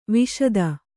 ♪ viśaḍa